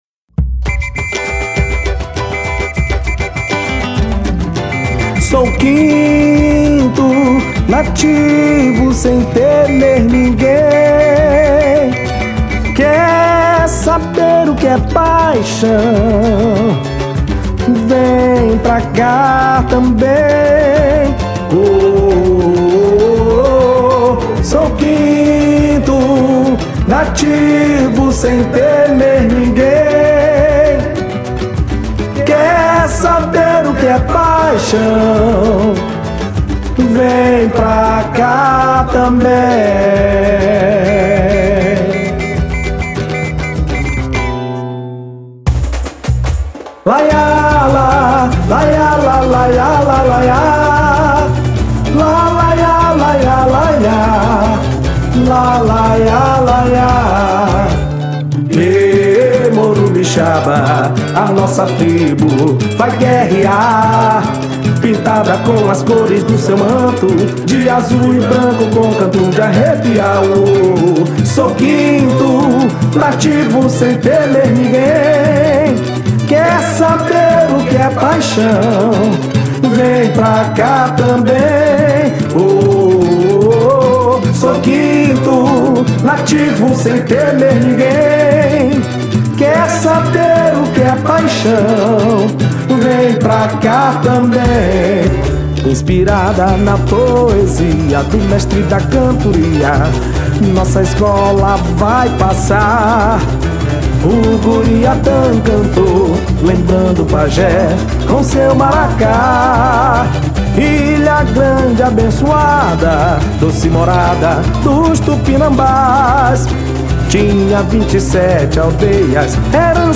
o samba enredo que vai ser cantado no carnaval de 2018.